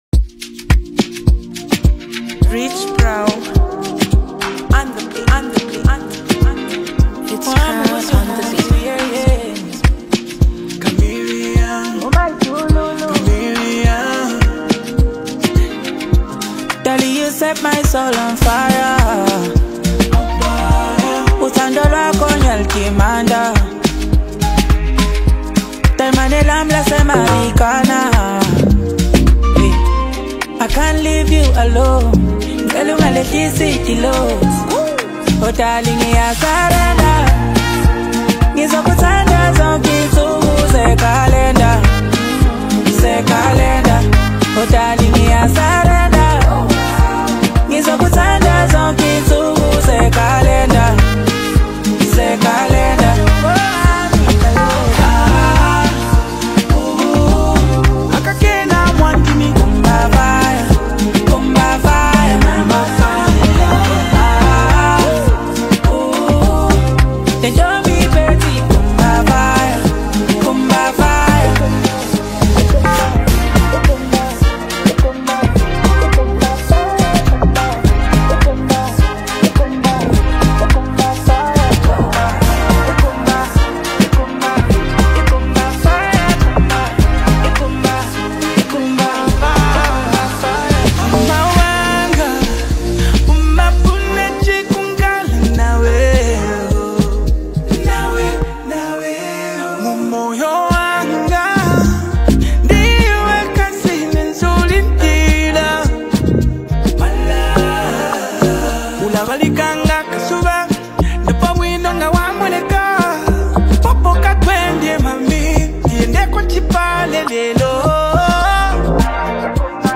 this track blends soulful melodies with Afrobeat energy